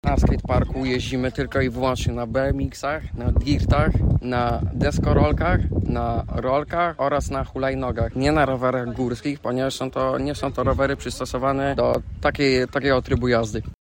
Kompleks nie jest dostosowany do każdego sprzętu. – jak mówi nam rowerzysta.